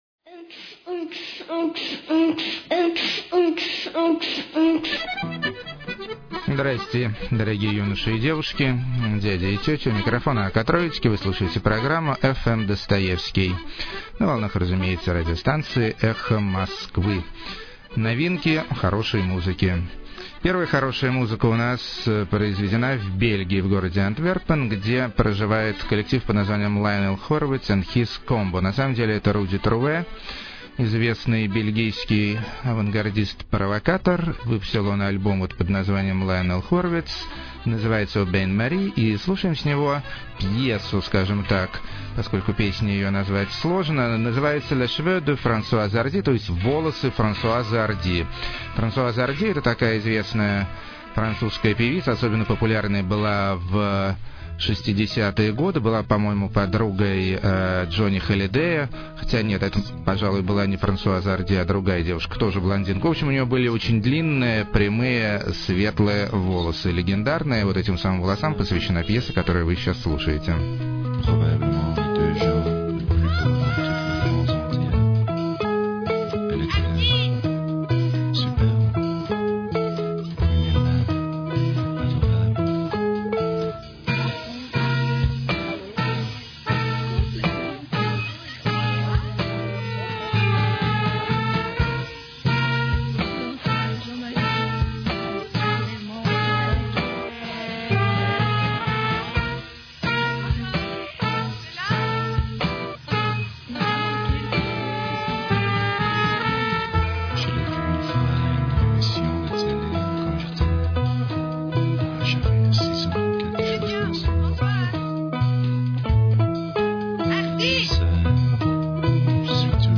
сильно усугубленный REM. душевная фисгармония.
напоминает Devo… помесь кантри с аналоговой электроникой.
– темный нордический Trip.]